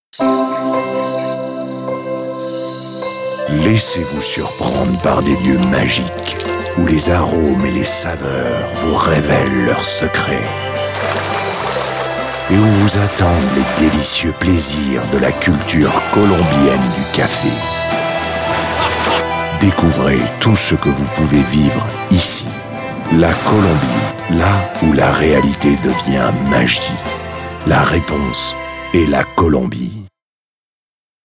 Male
Authoritative, Character, Conversational, Deep, Gravitas, Versatile, Warm
DEMO RAD US 2.mp3